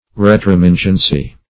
retromingency - definition of retromingency - synonyms, pronunciation, spelling from Free Dictionary
Search Result for " retromingency" : The Collaborative International Dictionary of English v.0.48: Retromingency \Re`tro*min"gen*cy\, n. The quality or state of being retromingent.